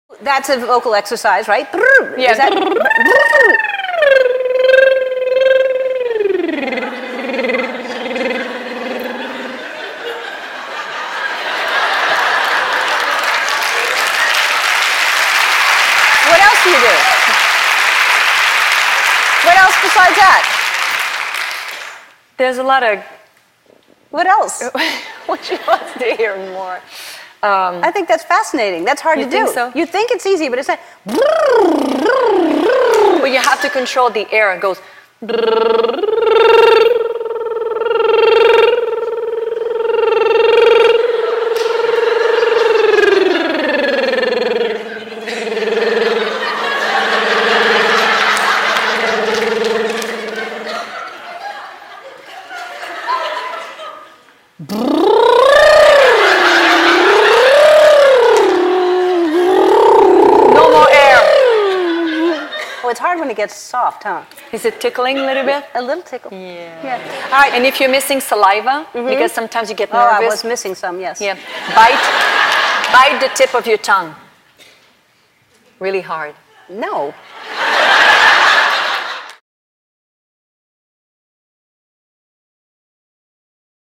在线英语听力室艾伦脱口秀13 Celine Dion Warms Up的听力文件下载,艾伦脱口秀是美国CBS电视台的一档热门脱口秀，而主持人Ellen DeGeneres以其轻松诙谐的主持风格备受青睐。